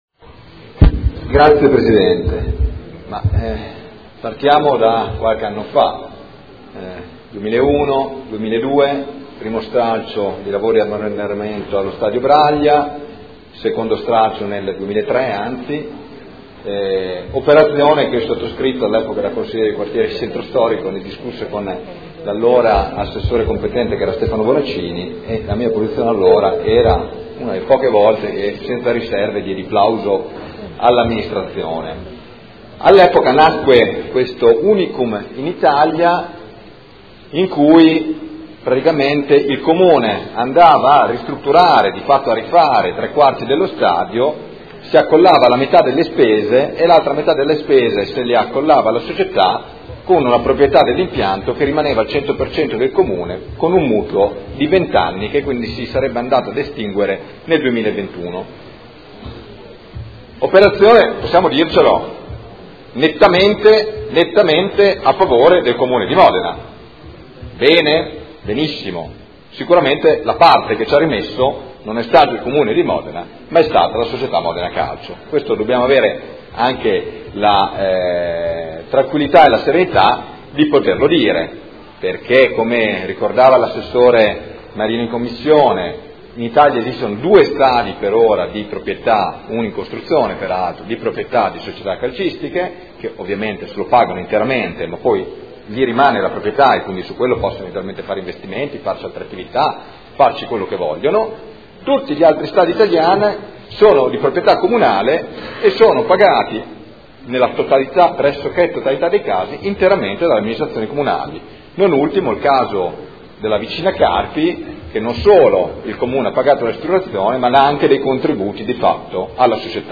Seduta del 7 aprile. Proposta di deliberazione: Convenzione per la gestione dello stadio comunale Alberto Braglia al Modena FC SpA – Prolungamento durata.